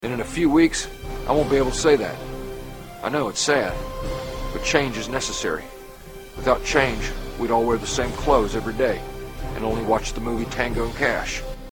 Category: Comedians   Right: Personal
Tags: You're Welcome America Will Ferrell George Bush Will Ferrell George Bush impersonation Will Ferrell George Bush